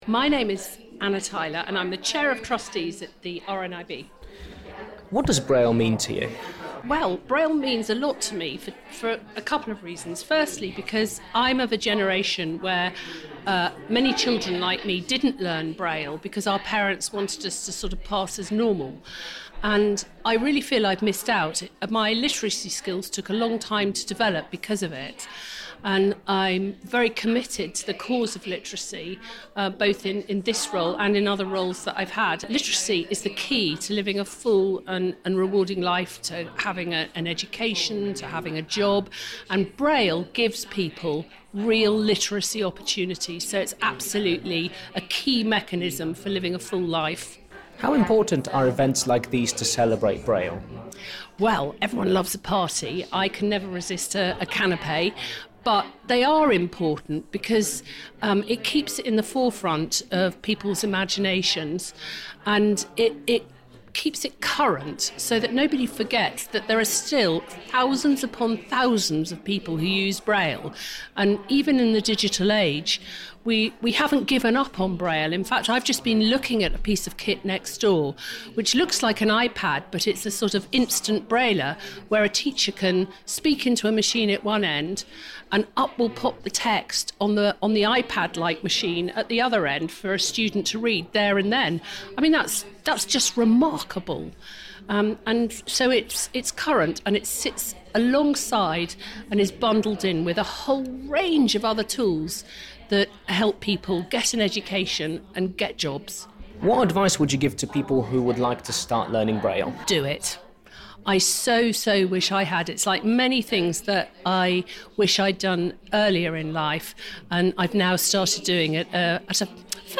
Charity RNIB and the British Library hosted an event to celebrate the bicentenary of the invention of braille, the system of raised dots that allows blind and partially sighted people to read with their fingers.